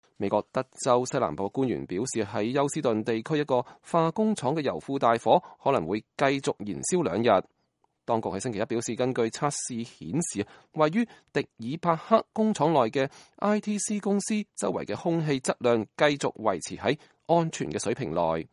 2019-03-19 美國之音視頻新聞: 美國德州油庫大火可能繼續燃燒兩天